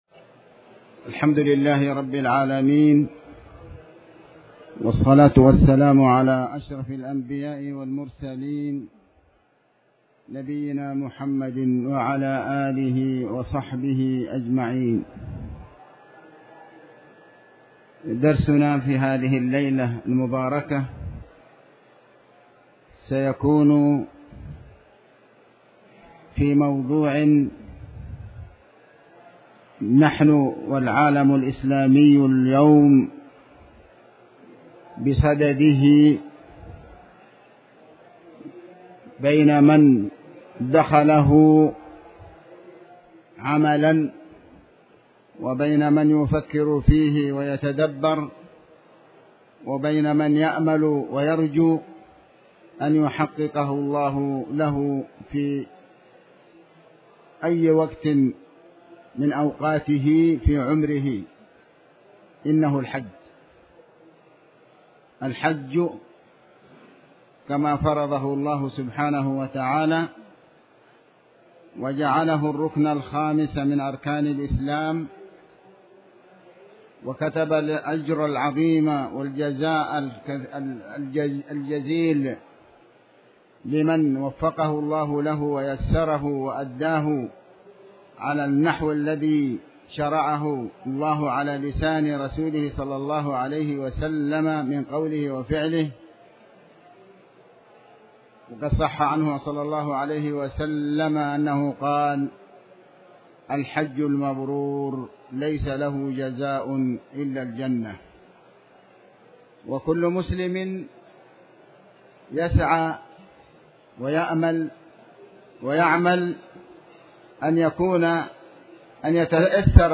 تاريخ النشر ٢٦ ذو القعدة ١٤٣٩ هـ المكان: المسجد الحرام الشيخ: علي بن عباس الحكمي علي بن عباس الحكمي كتاب الحج